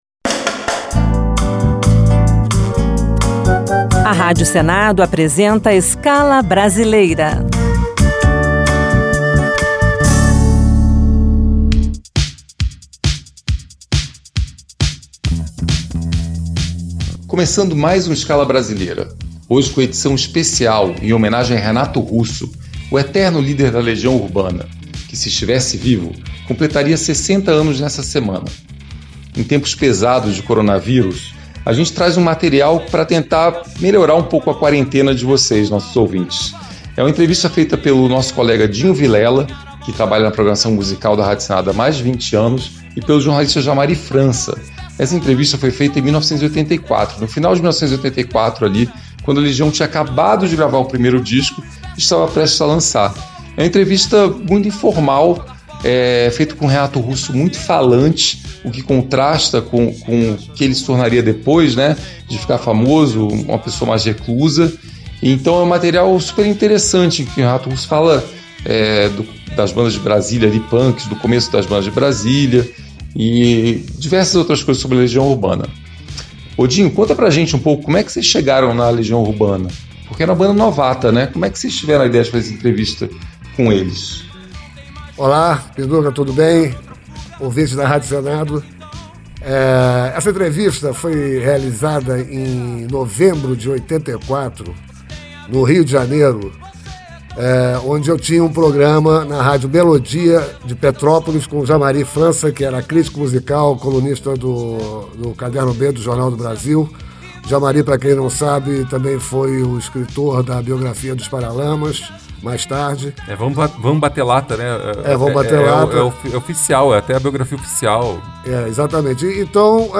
O clima era o mais informal possível
e registrada num gravador de rolo.